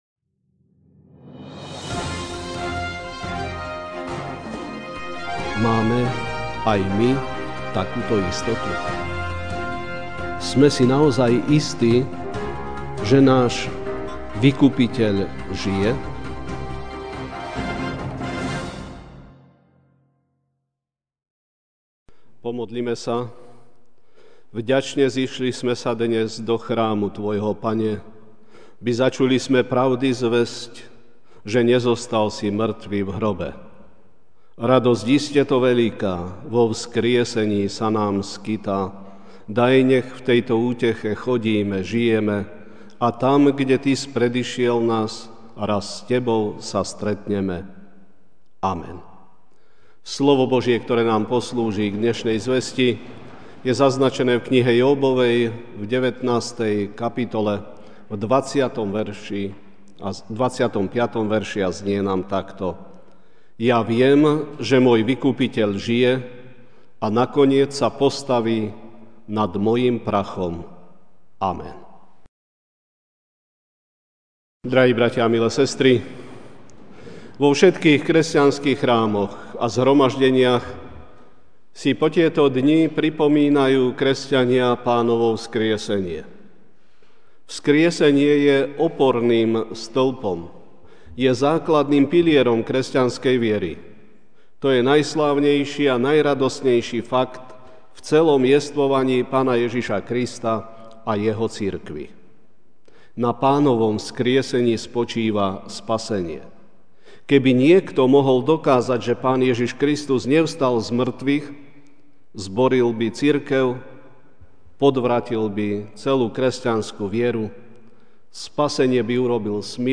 MP3 SUBSCRIBE on iTunes(Podcast) Notes Sermons in this Series Ranná kázeň: Môj vykupiteľ žije? (Jób 19, 25) Ja viem, že môj Vykupiteľ žije a nakoniec sa postaví nad prachom.